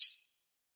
DDW4 PERC 6.wav